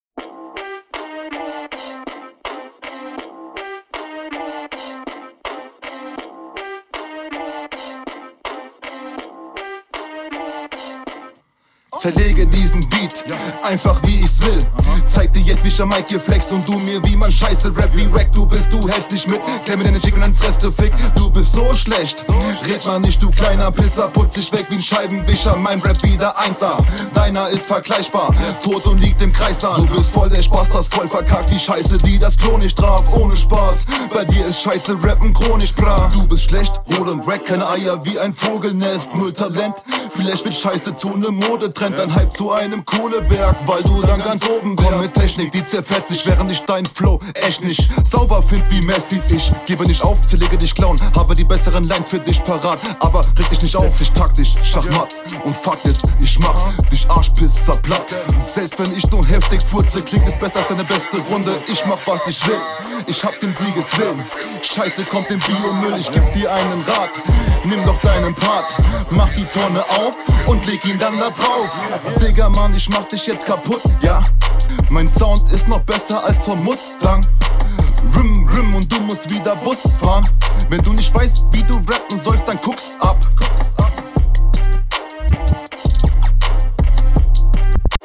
Flow: flow ist ganz cool stimmeimsatz auch gefällt mir Text: nichts wirklich besonderes, vlt 2 …
Flow: Hier zeigst du endlich mal ein paar Varriationen deines Flows, viele Zweckreime dabei aber …